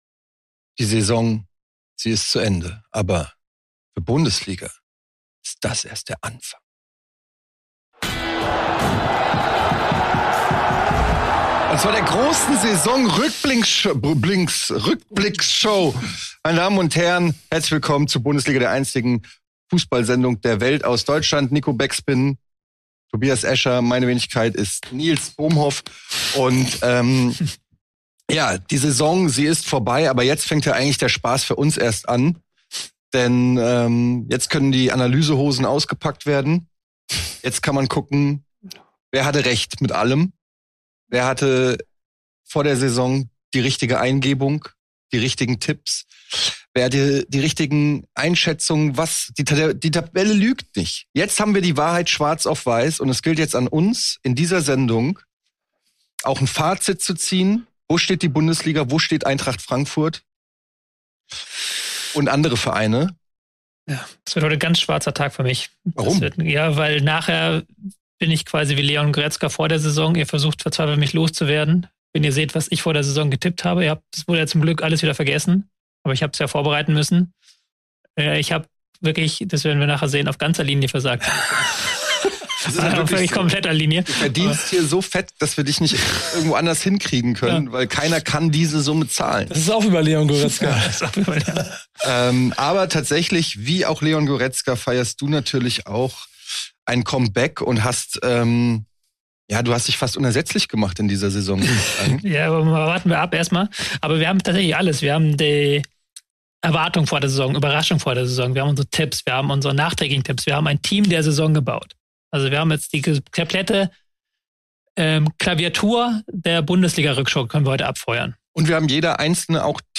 Bei uns gibt es nur harte Fakten und hitzige Diskussionen.